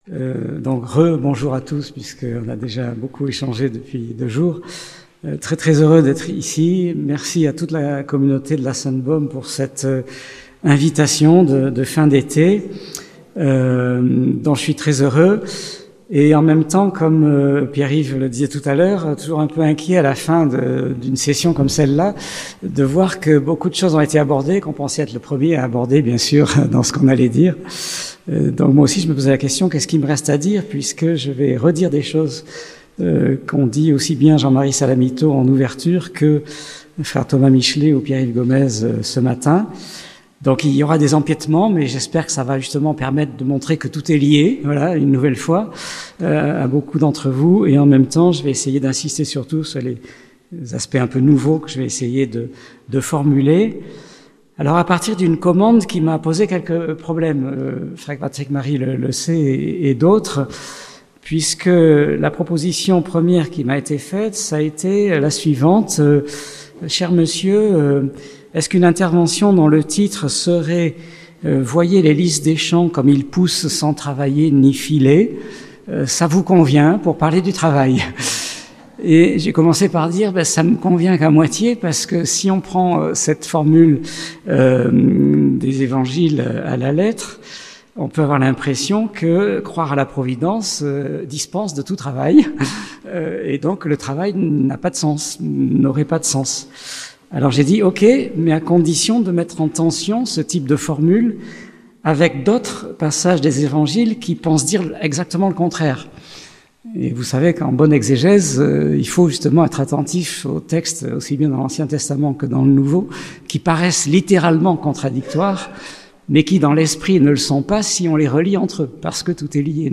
Université d'été